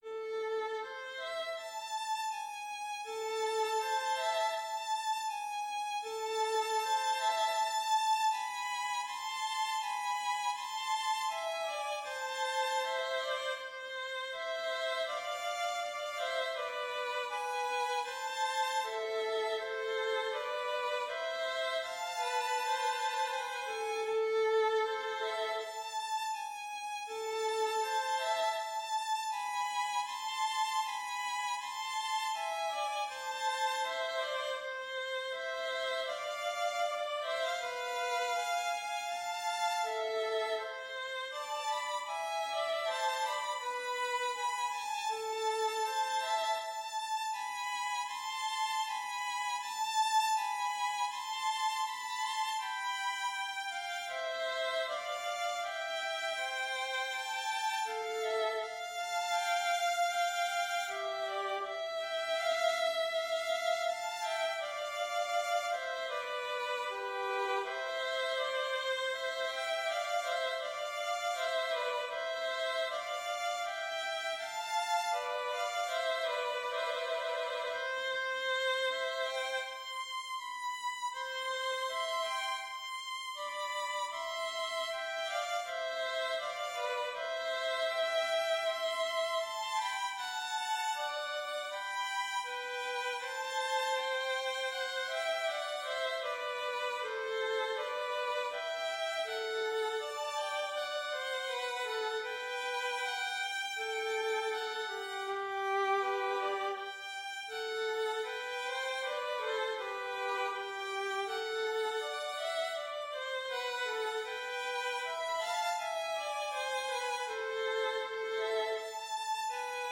Intermediate Violin Duet